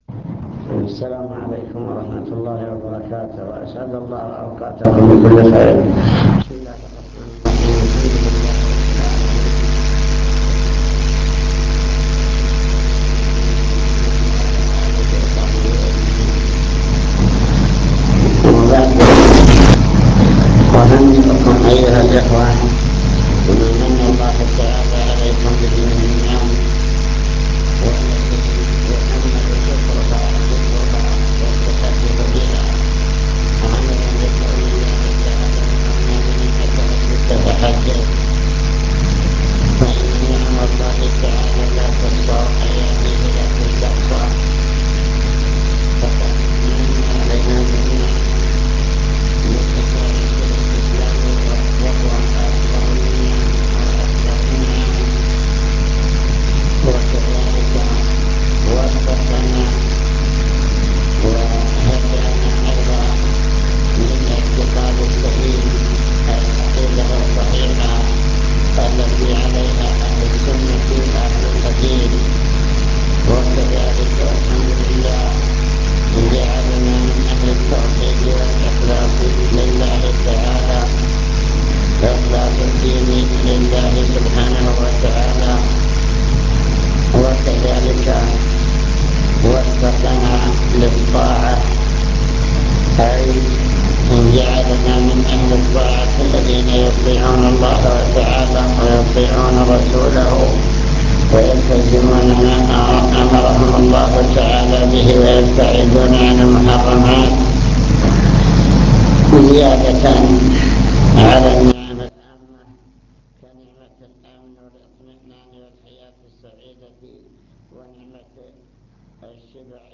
المكتبة الصوتية  تسجيلات - محاضرات ودروس  محاضرة القاعة